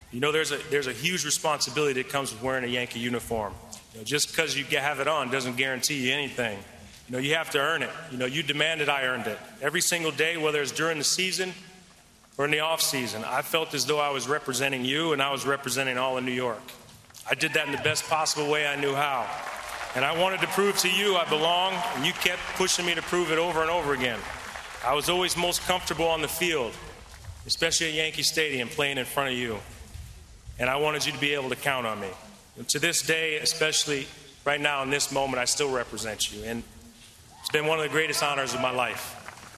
In front of an adoring crowd, he said how much being a Yankee meant to him.
MLB-HOF-Representing-Yankees-Fans.mp3